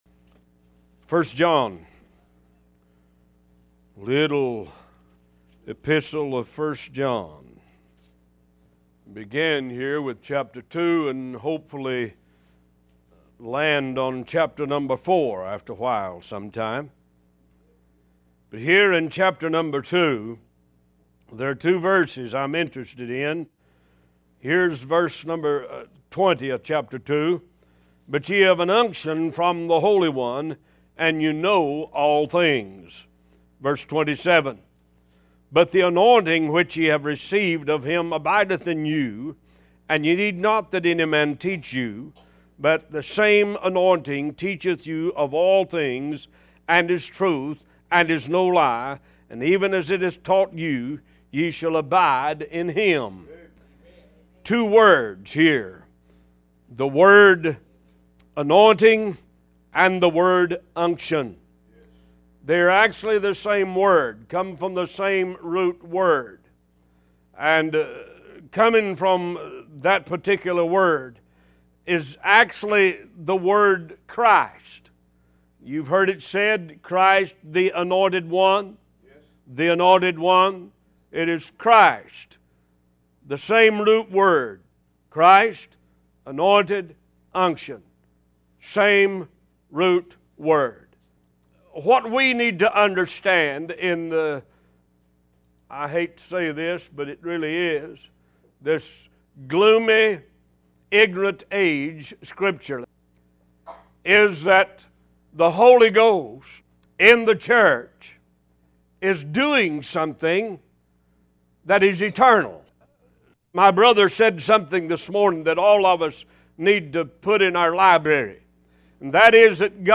One Voice Talk Show